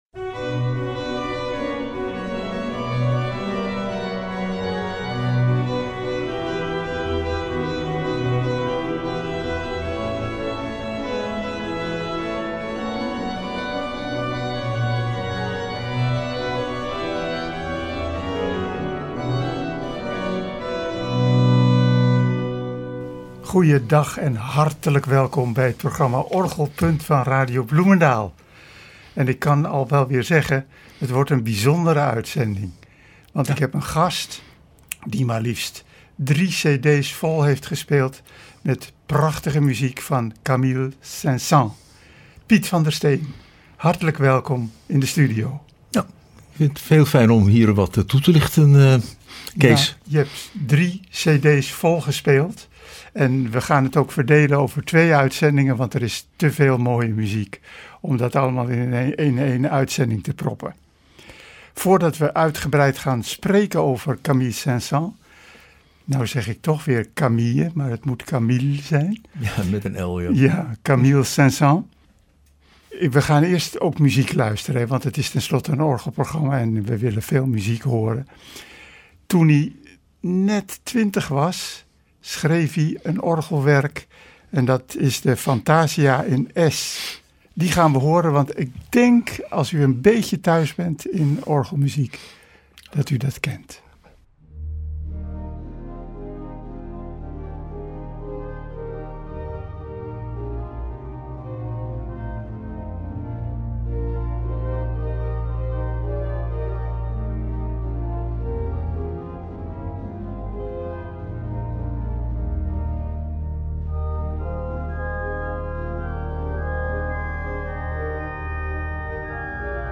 En hij vertolkt die op een fraai Cavaillé-Coll orgel., dat in de Notre Dame van de Franse stad Épernay staat.
Maar ook zijn op de cd’s allerlei andere orgel gerelateerde instrumenten te horen, zoals een harmonium, een zogeheten Poikil-orgue , een Aeolian organ en een Erard-vleugel .